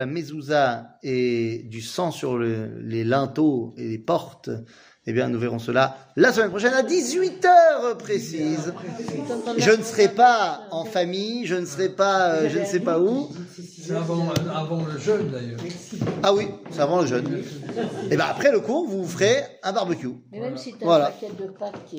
Livre de Chemot, chapitre 12, verset 17 00:30:44 Livre de Chemot, chapitre 12, verset 17 שיעור מ 13 מרץ 2024 30MIN הורדה בקובץ אודיו MP3 (344.53 Ko) הורדה בקובץ וידאו MP4 (3.1 Mo) TAGS : שיעורים קצרים